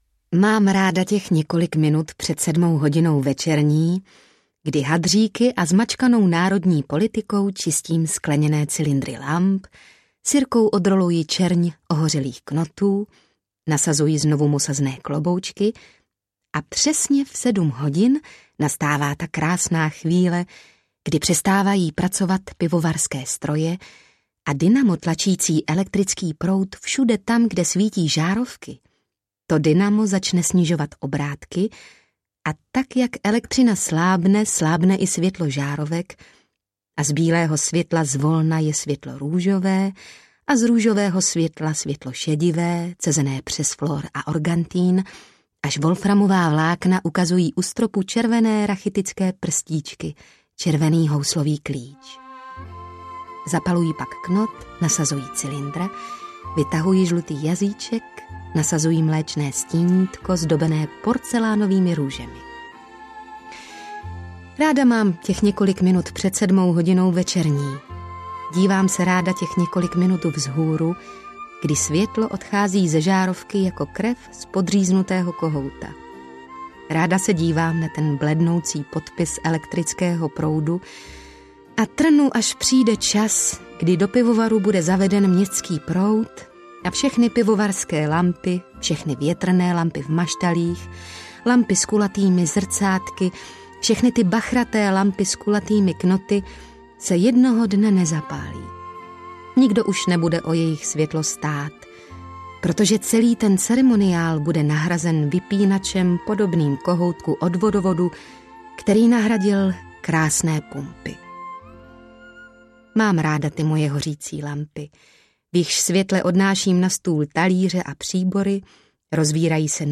Postřižiny audiokniha
Ukázka z knihy
• InterpretTereza Bebarová